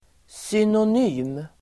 Uttal: [synon'y:m]